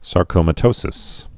(sär-kōmə-tōsĭs)